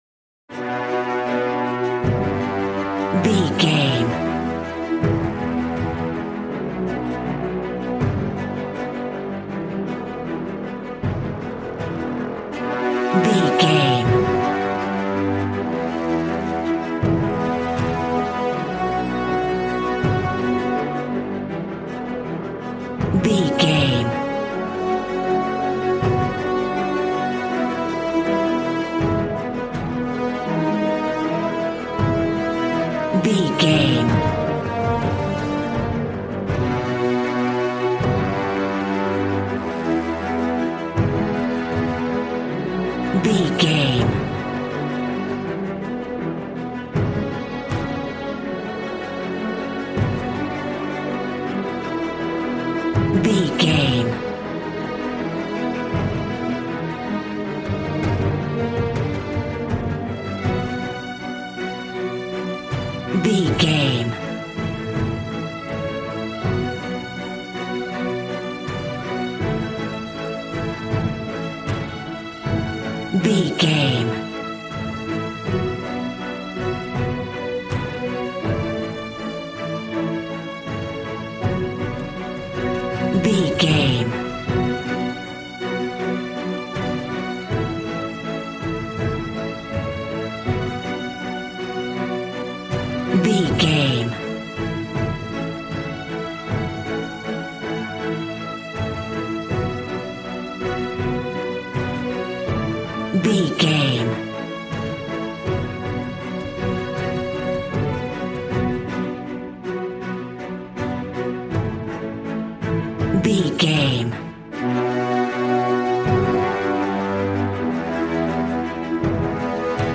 suspense
piano
synthesiser